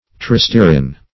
Tristearin \Tri*ste"a*rin\, n.